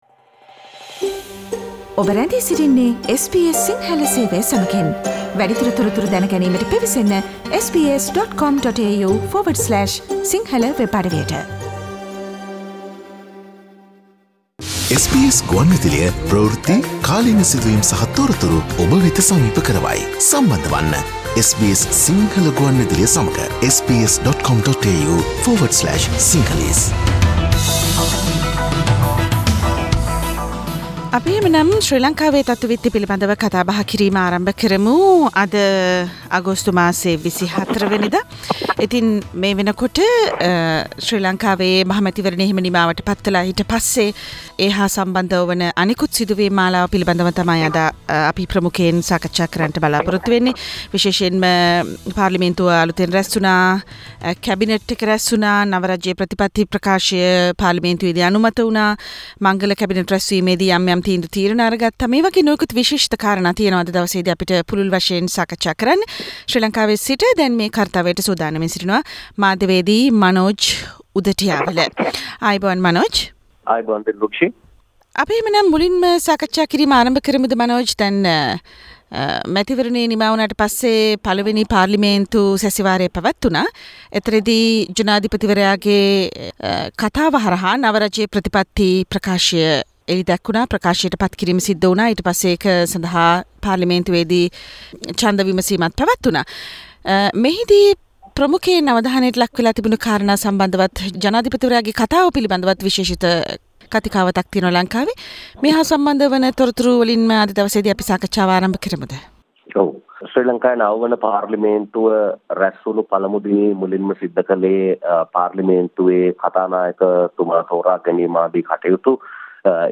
Hints from Dayasiri about Maithri's new position in the new government and more : weekly Sri Lankan news wrap